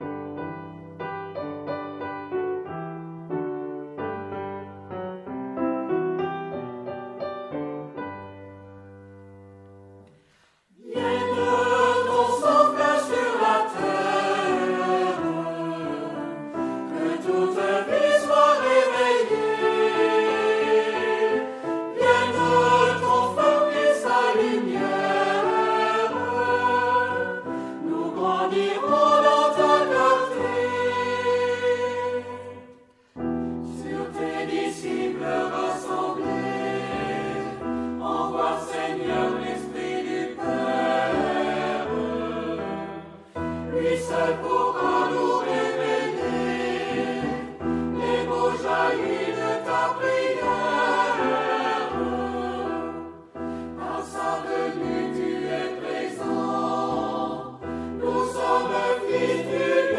Genre-Style-Form: Sacred ; Hymn (sacred)
Mood of the piece: forceful
Type of Choir: SATB  (4 mixed voices )
Instruments: Organ (1)
Tonality: G major